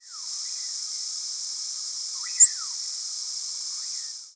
Vesper Sparrow nocturnal
presumed Vesper Sparrow nocturnal flight calls